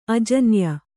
♪ ajanya